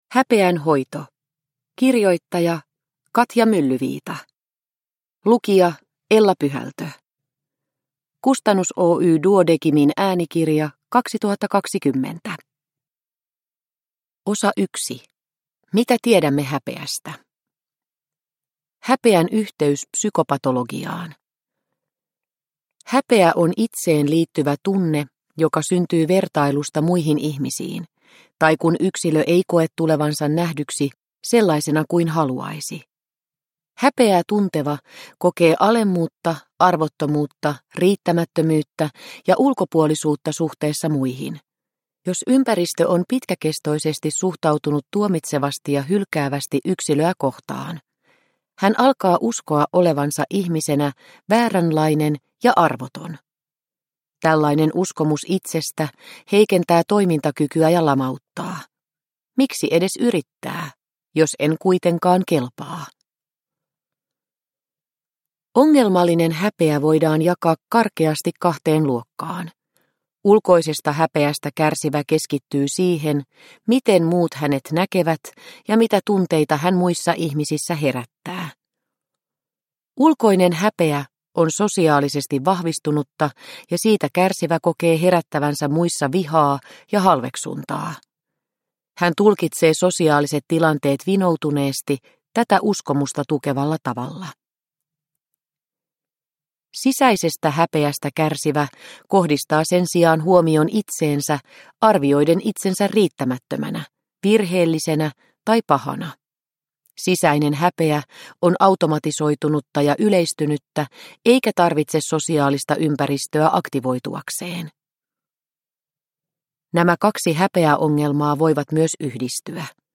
Häpeän hoito – Ljudbok – Laddas ner